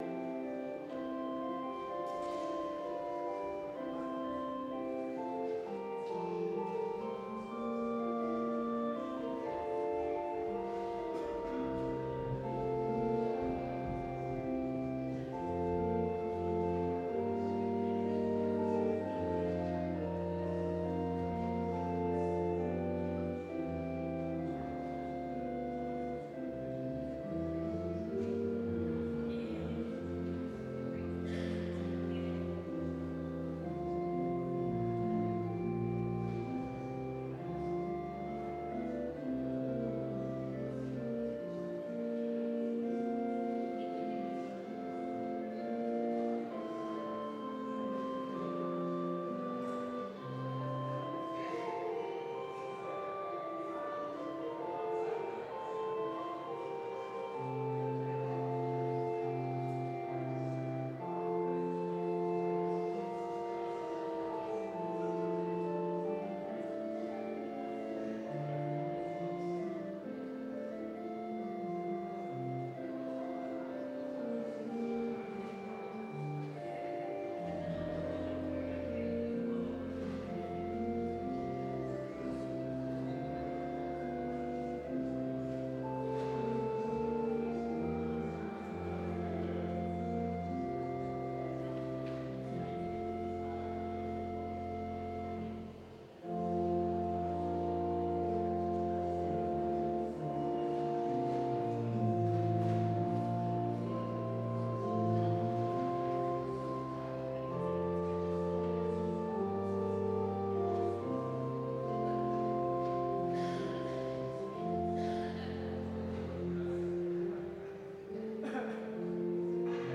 Full Service Audio